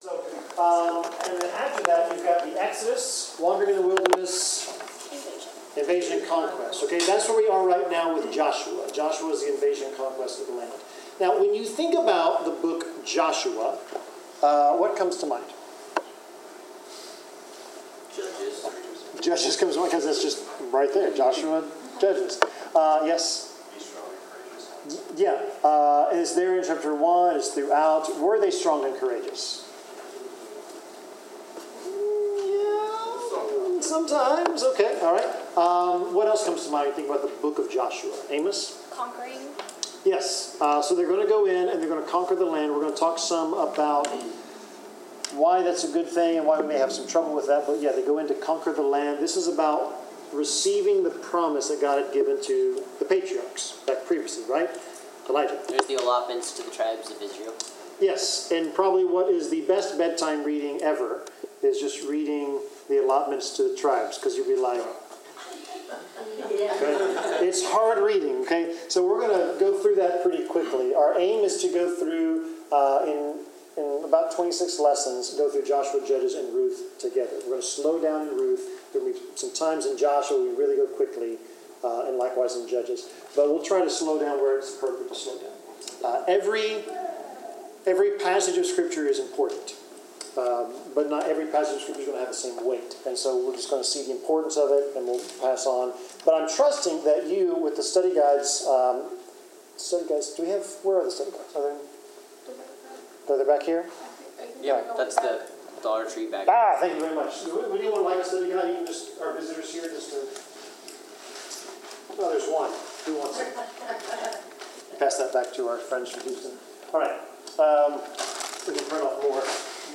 Bible class: Introduction to the Book of Joshua
Service Type: Bible Class Topics: Bible , Courage , Faith , God's Commandments , Inheritance , Obedience , Promises of God , Prophecy